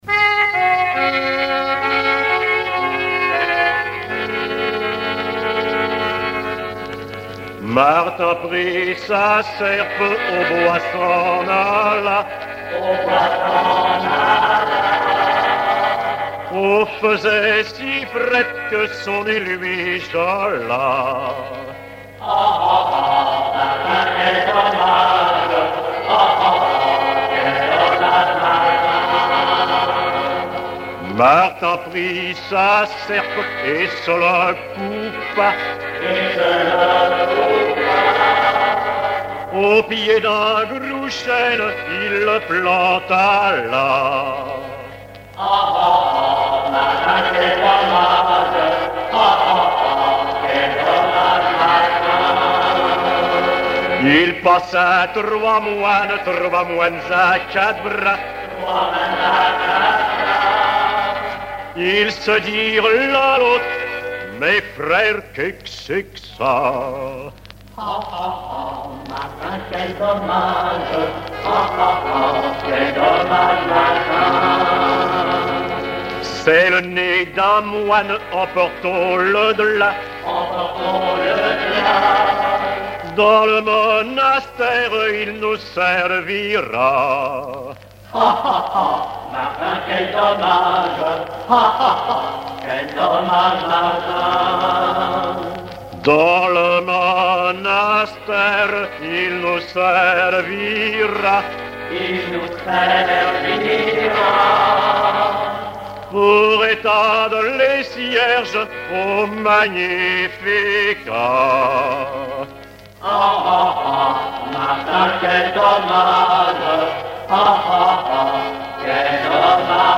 Langue Patois local
Genre laisse
Pièce musicale inédite